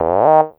45 SYNTH 5-R.wav